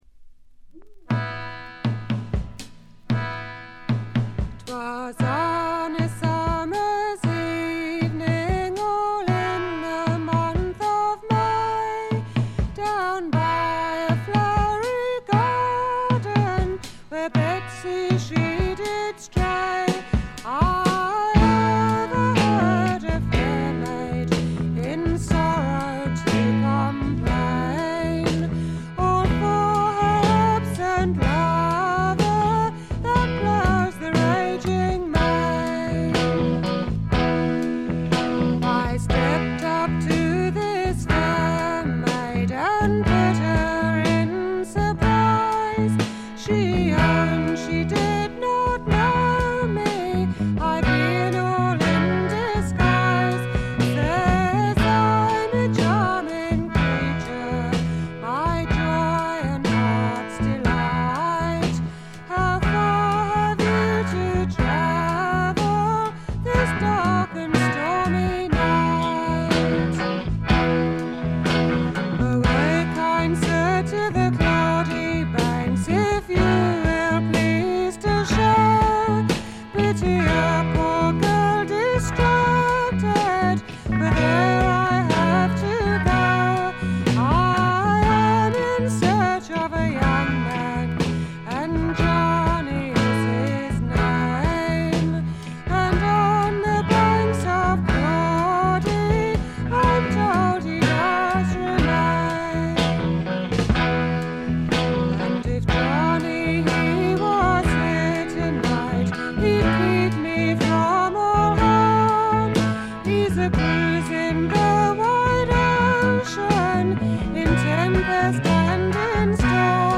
英国フォーク、エレクトリック・トラッドに興味があるなら避けては通れない歴史的名盤。
エレクトリック・トラッド最高峰の一枚。
試聴曲は現品からの取り込み音源です。